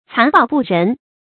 残暴不仁 cán bào bù rén 成语解释 凶狠毒辣，一点也没有同情、怜悯之心。